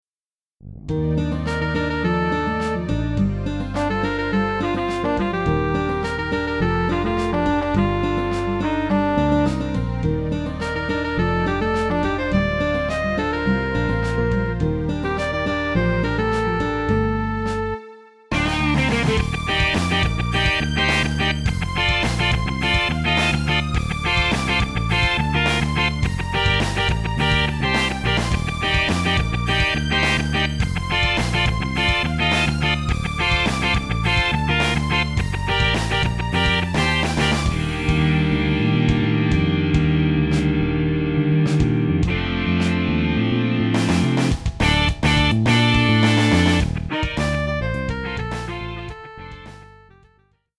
爽やかダークロック。(イミフ)